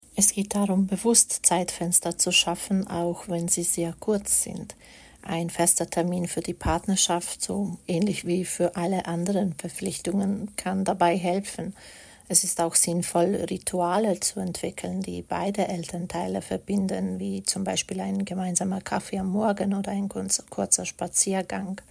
Dieses Interview gibt es auch auf Schwitzerdütsch!